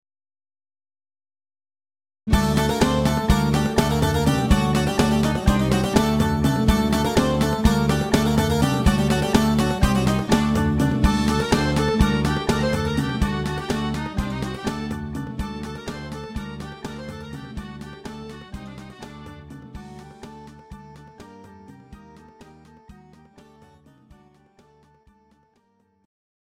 Greek Folk Kamilieriko Zeimpekiko 9/8